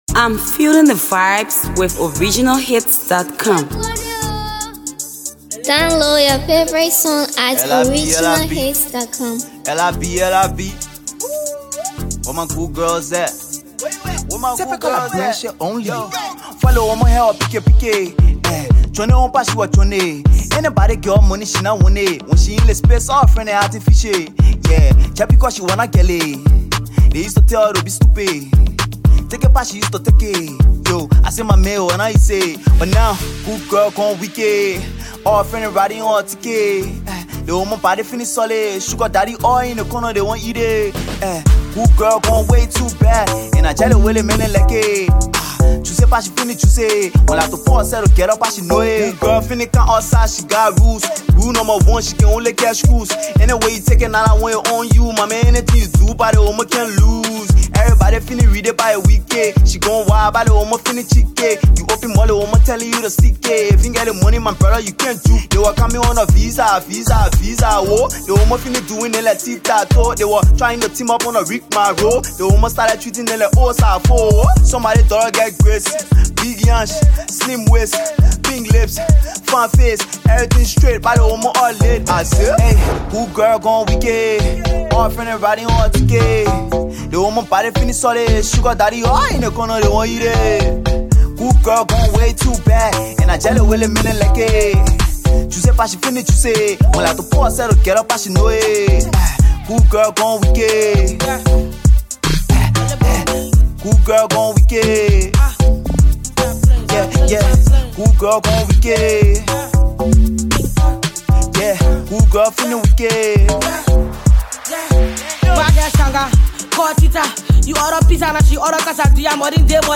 female rapper